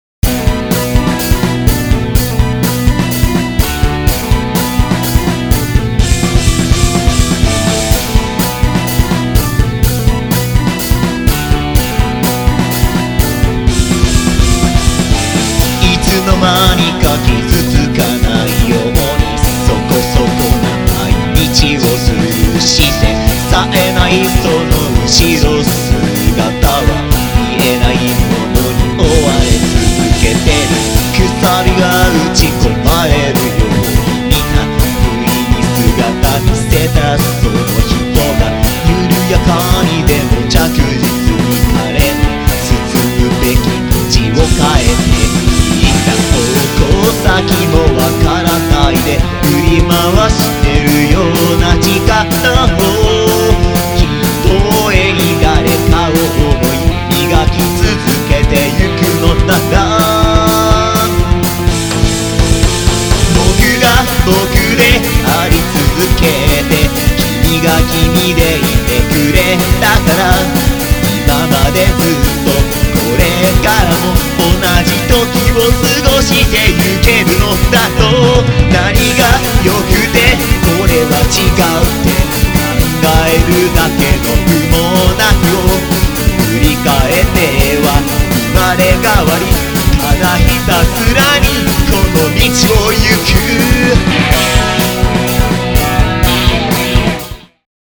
今回も爽快感のあるアッパーサウンドで脳天をぶち上げていきます！！
※視聴用音源は製品版と音質など異なる場合があります。